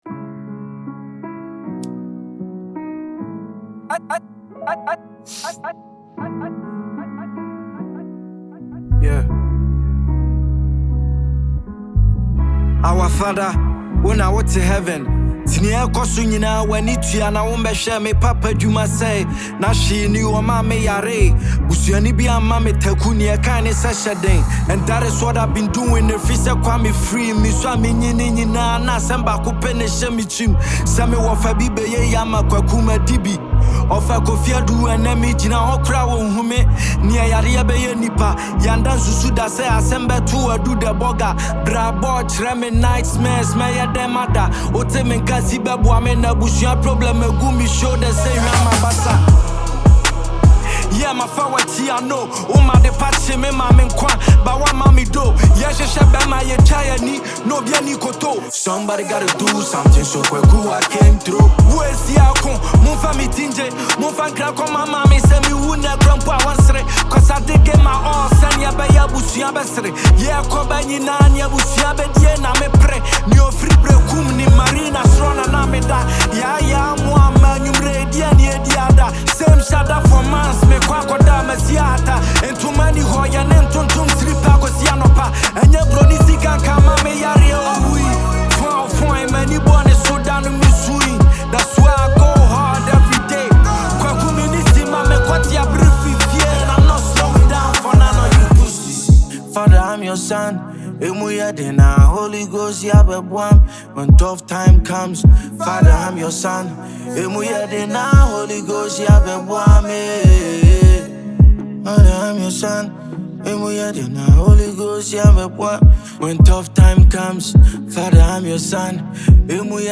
a Ghanaian rapper